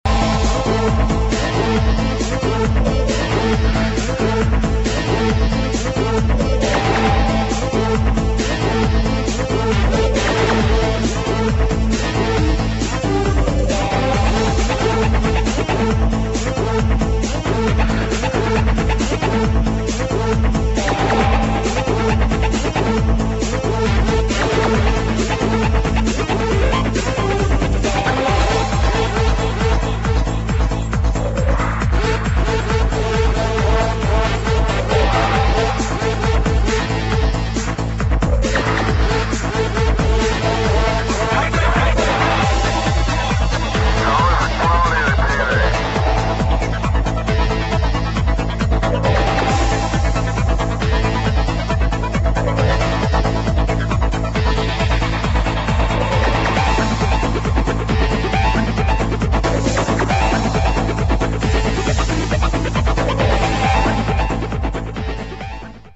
[ BREAKS ]